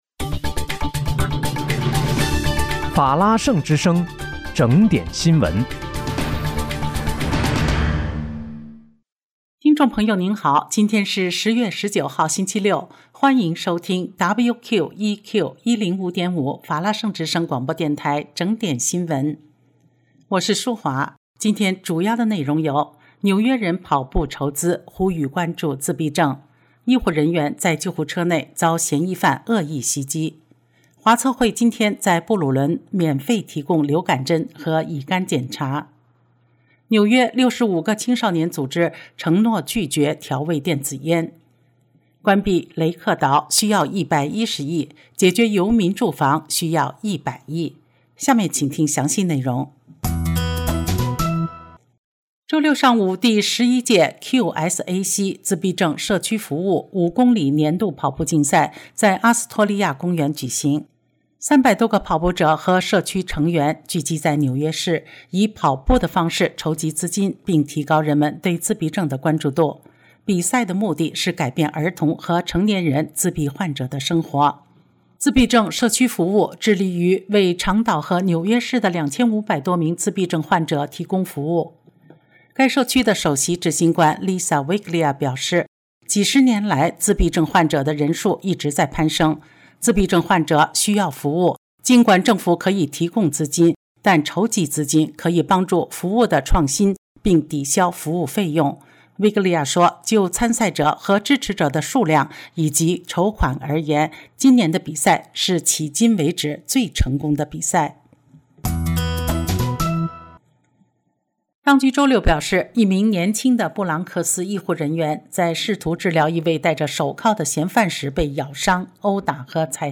10月19日（星期六）纽约整点新闻